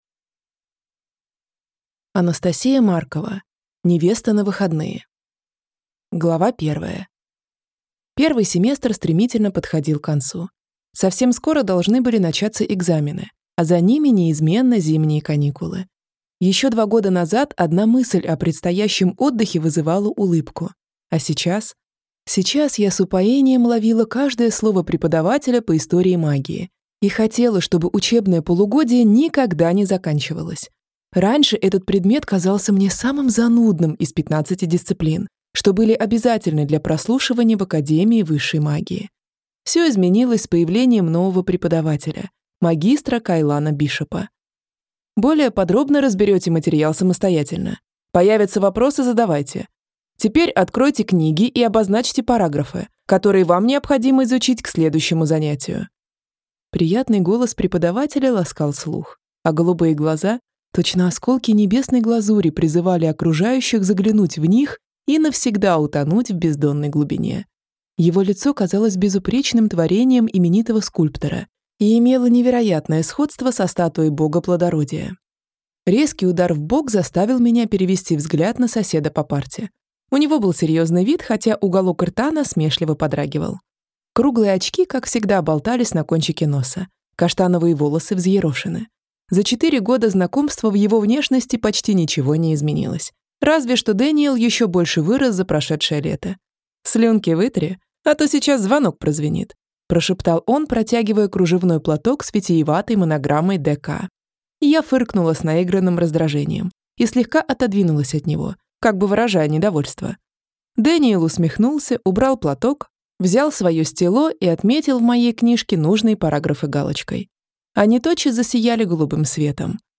Аудиокнига Невеста на выходные | Библиотека аудиокниг